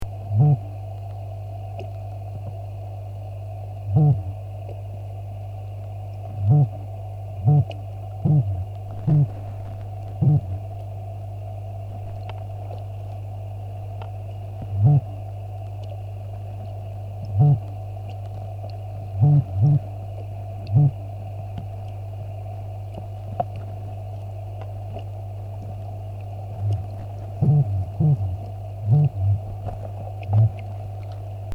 Click here to listen to the noises this species makes.
liosomadoras_morrowi.mp3